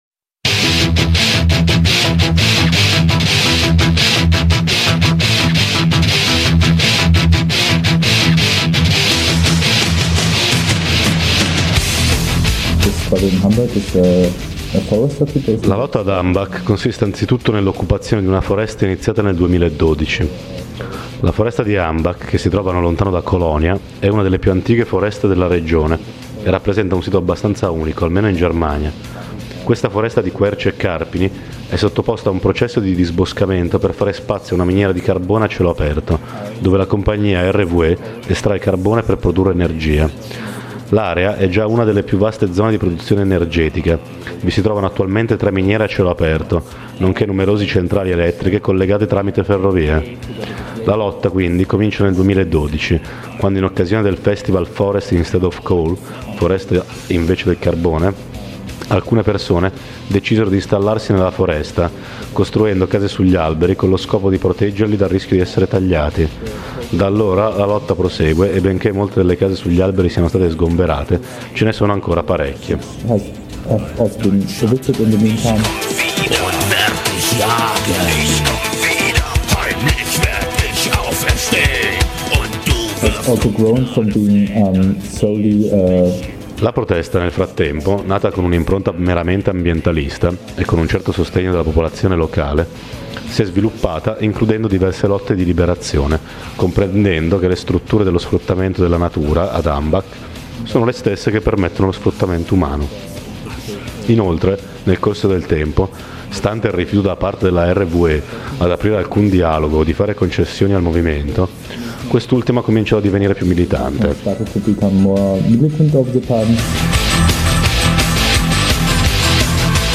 Un’intervista con un compagno attivo nella lotta alla foresta di Hambach, in Germania. Da cinque anni, parte della popolazione locale e alcuni solidali hanno dato vita a un accampamento permanente con lo scopo di salvare l’ultima parte di bosco rimasta intatta dalla voracità espansiva della miniera di carbone gestita dalla compagnia RWE.